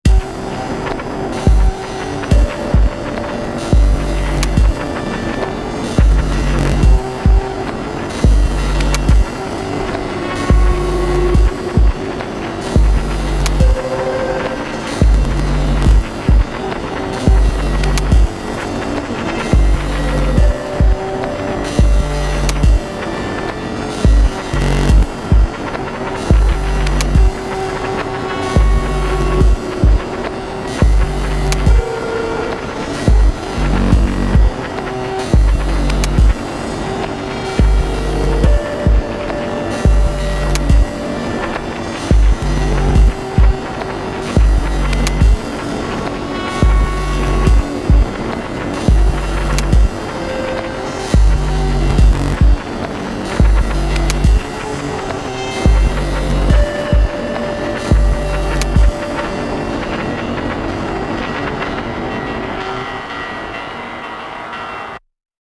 that tonverk sound…